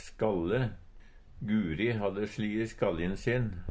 Sjå òg huku (Nore) Høyr på uttala Ordklasse: Substantiv hankjønn Kategori: Kropp, helse, slekt (mennesket) Attende til søk